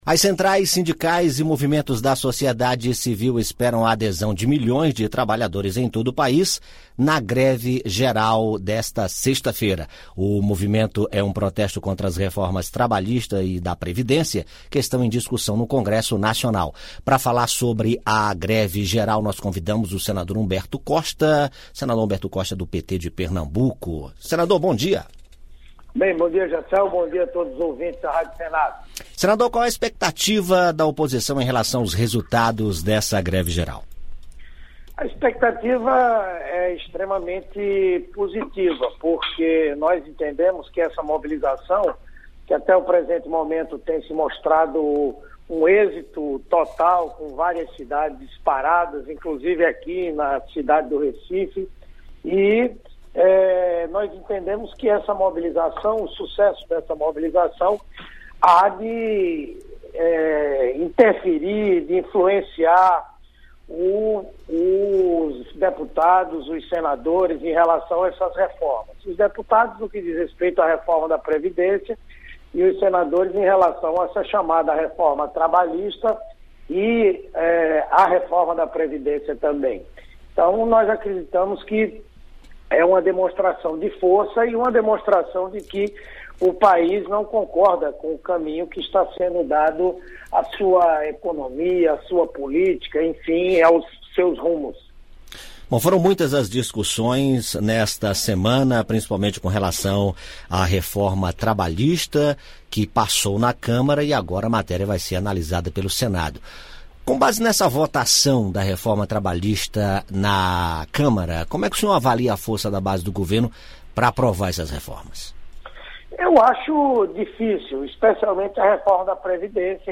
Líder da minoria no Senado, o senador Humberto Costa (PT-PE) afirmou, em entrevista à Rádio Senado, que a greve geral convocada pelas centrais sindicais para esta sexta-feira (28) está sendo um "êxito total". O movimento protesta contra as reformas trabalhista e da Previdência, em discussão no Congresso.